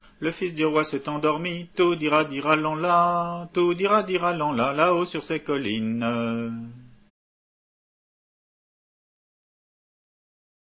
Entendu au festival de Lorient en août 90